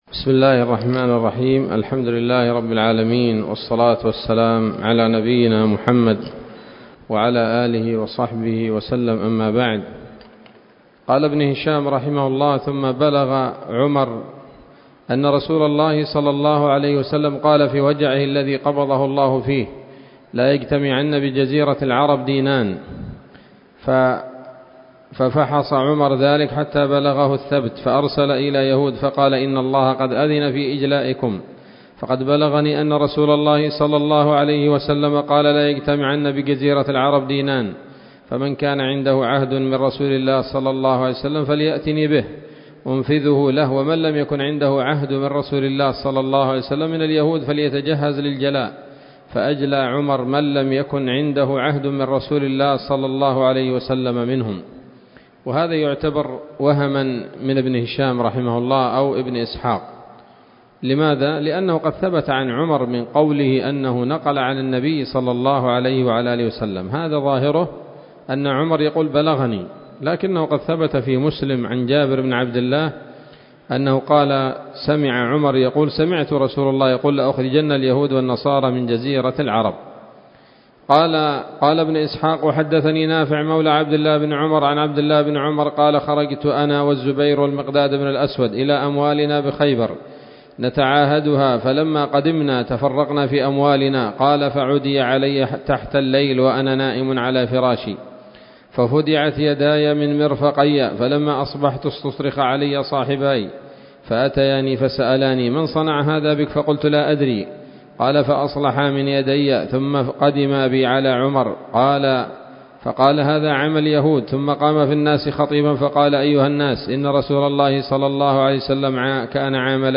الدرس الثامن والأربعون بعد المائتين من التعليق على كتاب السيرة النبوية لابن هشام